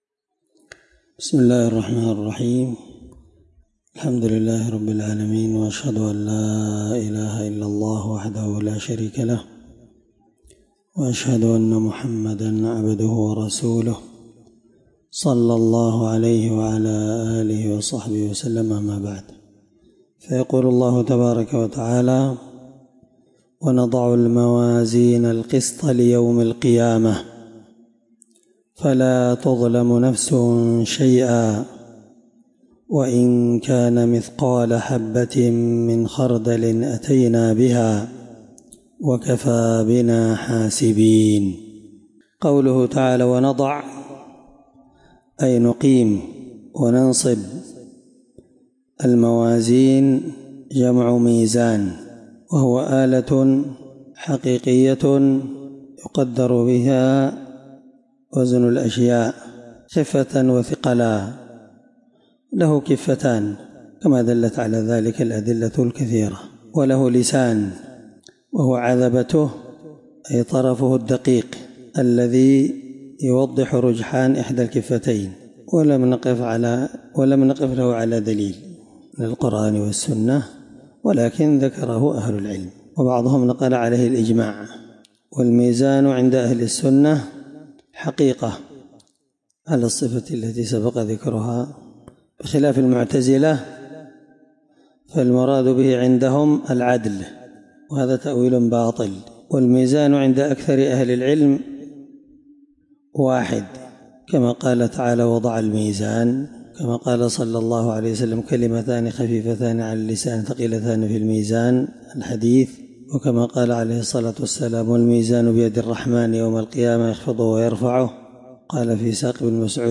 الدرس16تفسير آية (47) من سورة الأنبياء
21سورة الأنبياء مع قراءة لتفسير السعدي